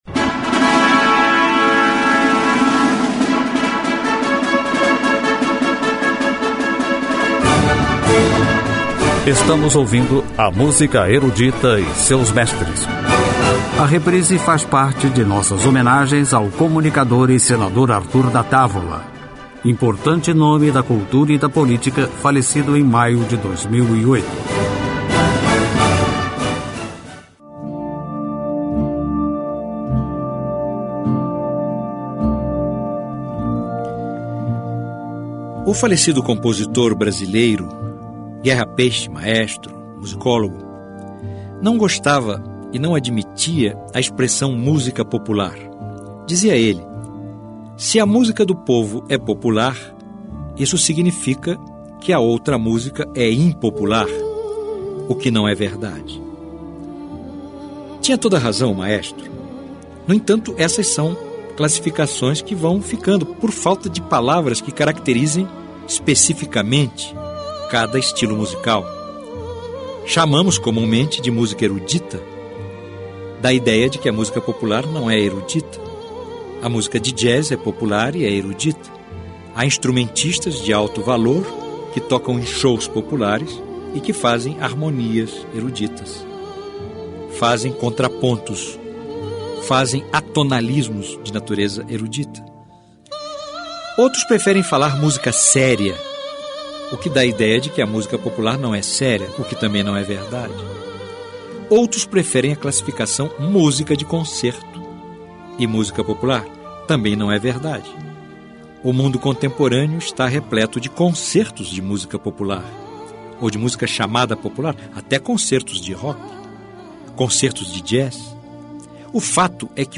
Música Erudita
Cantores líricos